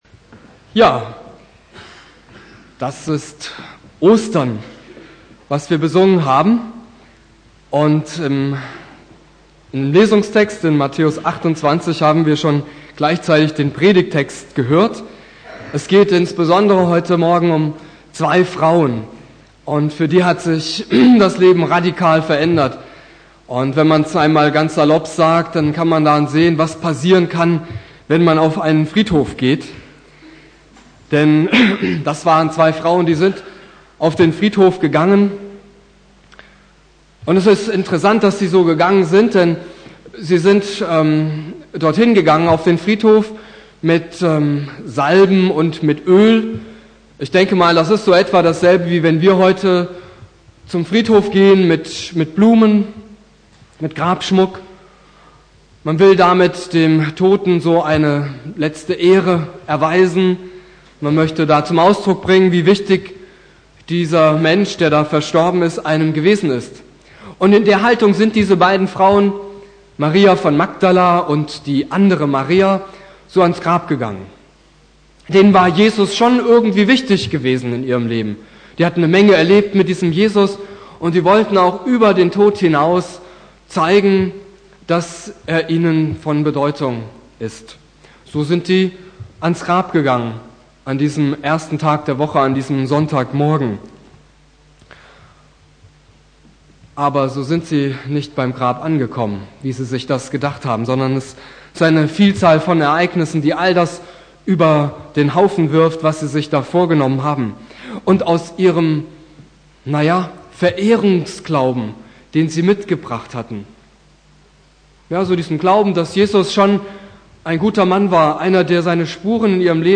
Predigt
Ostersonntag